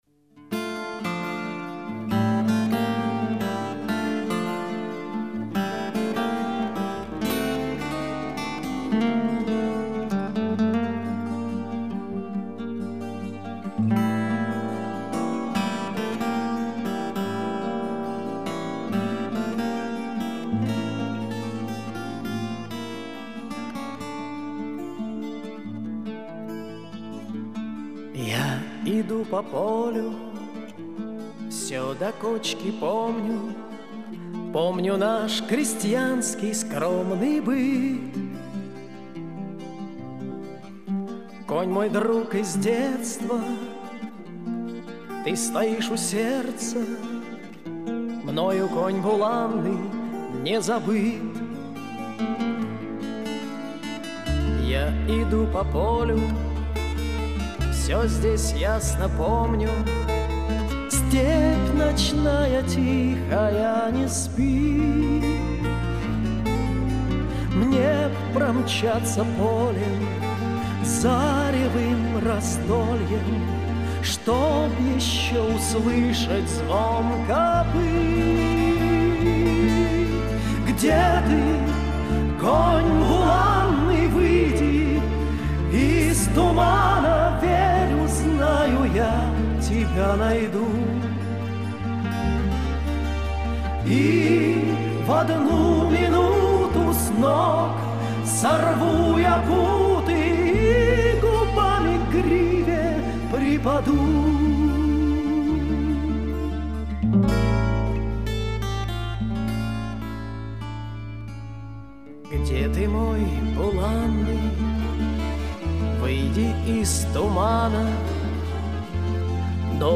Качество не важнецкое